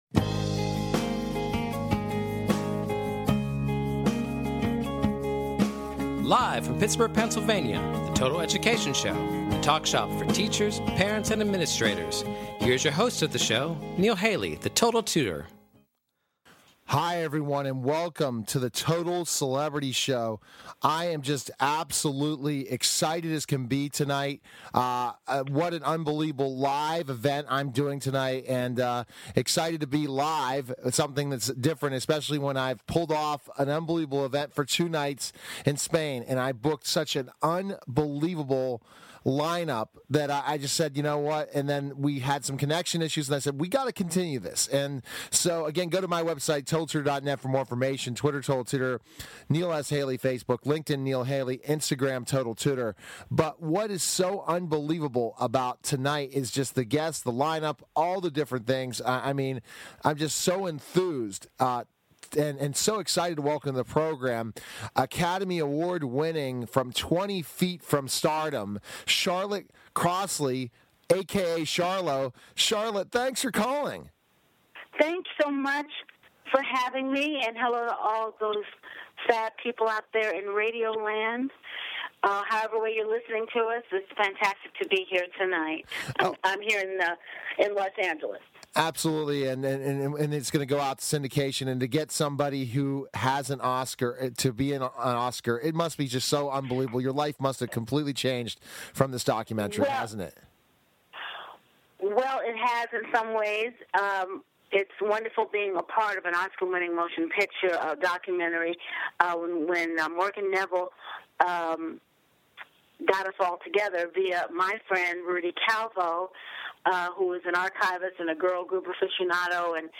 Talk Show Episode, Audio Podcast, Total_Education_Show and Courtesy of BBS Radio on , show guests , about , categorized as